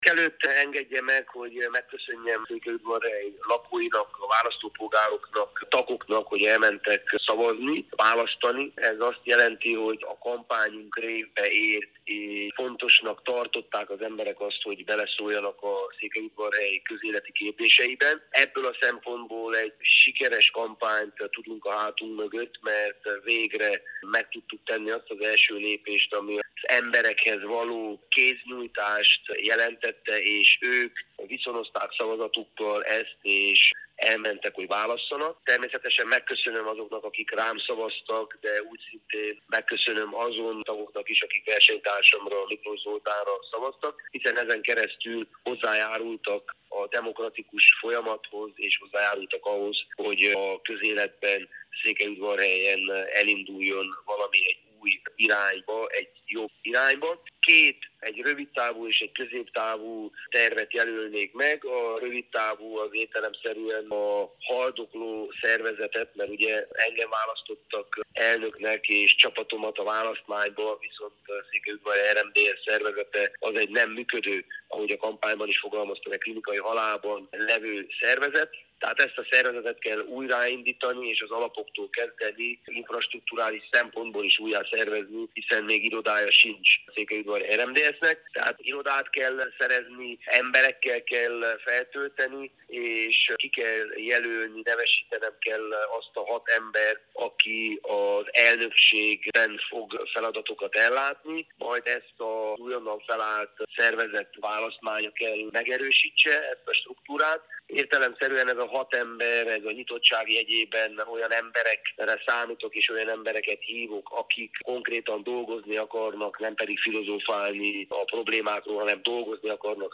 Antal Lóránt szenátor, a székelyudvarhelyi RMDSZ újdonsült elnöke a következőket nyilatkozta a Rádiónknak.